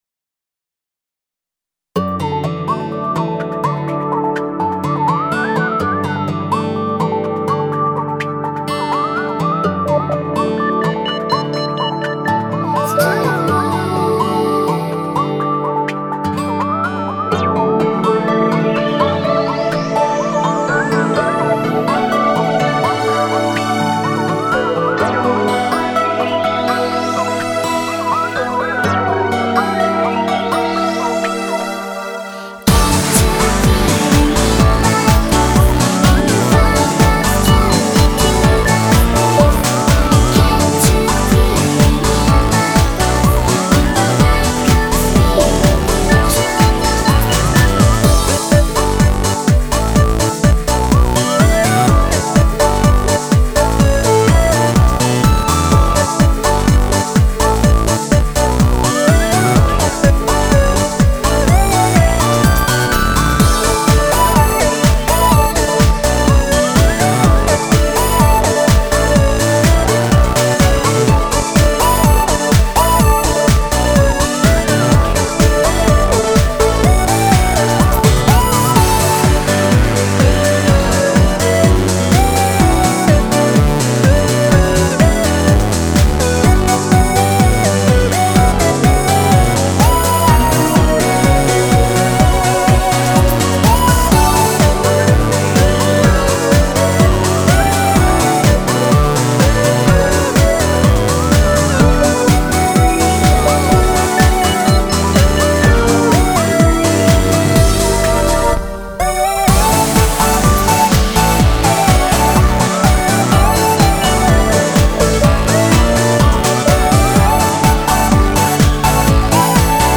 Aqui deixo também a versão instrumental: